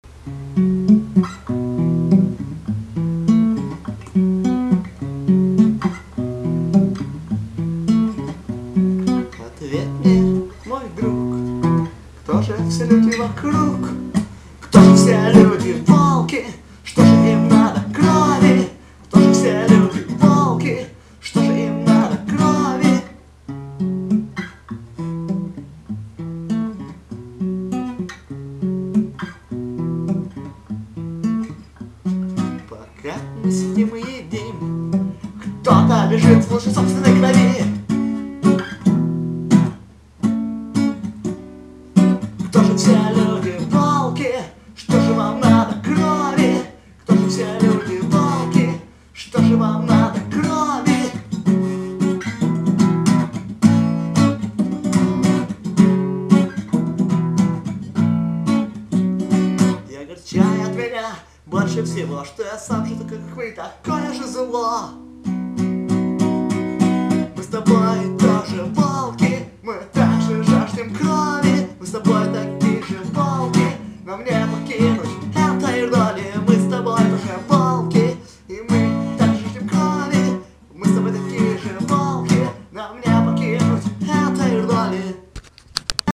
Категория: Акустика